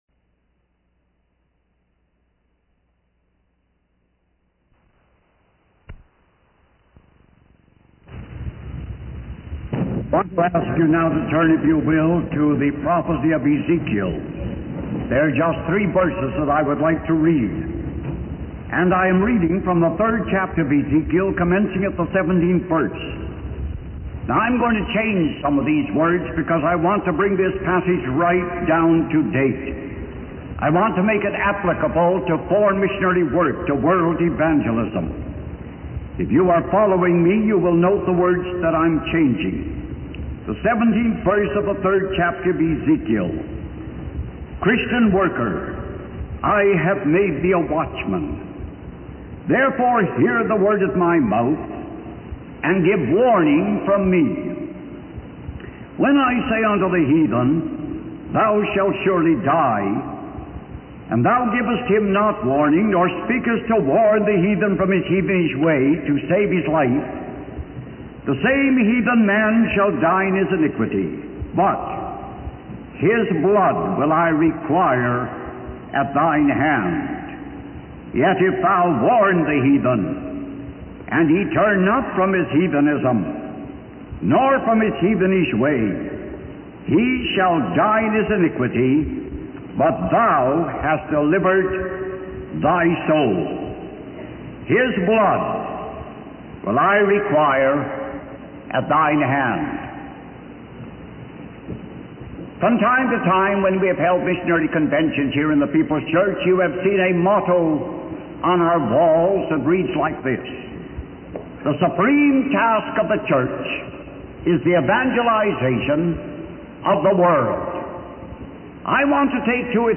In this sermon, the speaker emphasizes the supreme task of the Church, which is the evangelization of the world. He highlights the importance of having a world vision and not just focusing on local matters. The speaker quotes Bible verses to support his message, emphasizing that God's love and Jesus' sacrifice were for the entire world.